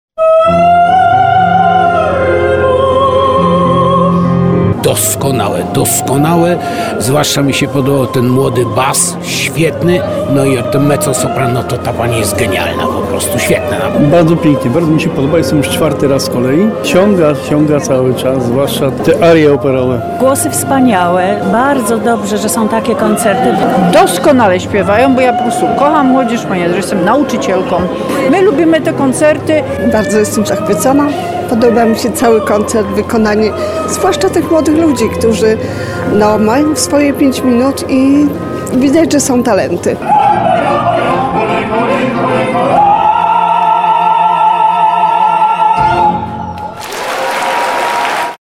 Sala Centrum Sztuki Mościce w Tarnowie wypełniła się po brzegi. Podczas koncertu świąteczno-noworocznego publiczność usłyszała arie operowe i operetkowe.